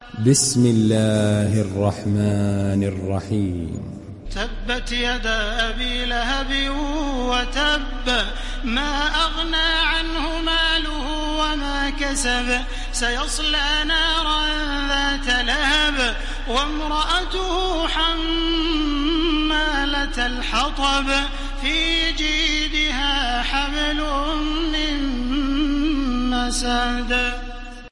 Download Surah Al Masad Taraweeh Makkah 1430